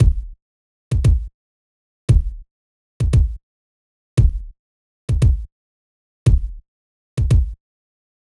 01 Kick Drum.wav